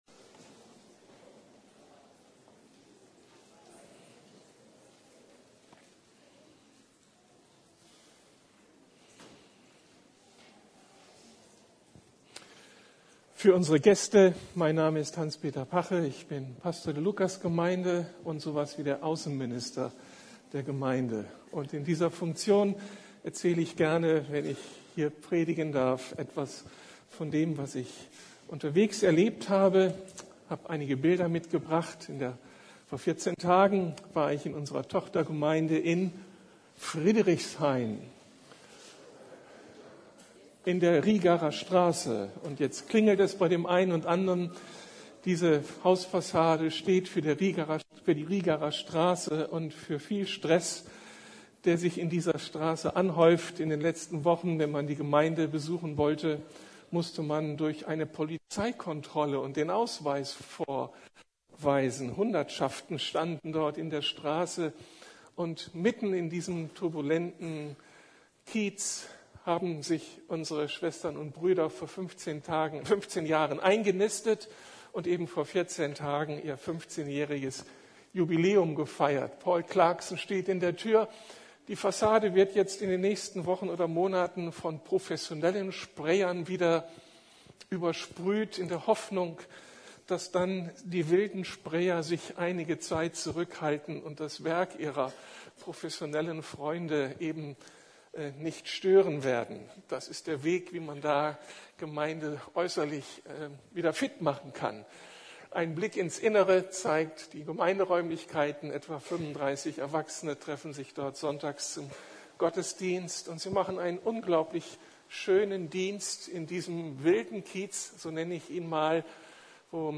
Vor dem Leben kommt das Sterben! ~ Predigten der LUKAS GEMEINDE Podcast